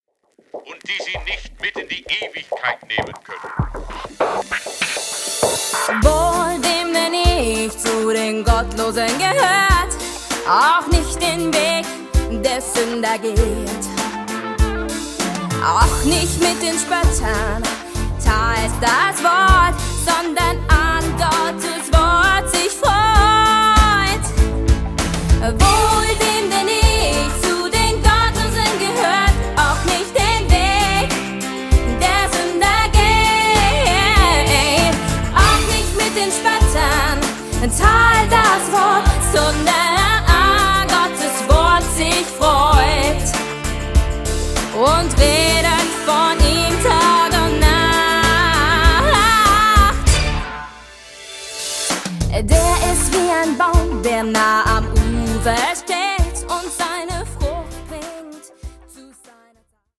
Einfach up-to-date-Grooves mit positiver Ausstrahlung!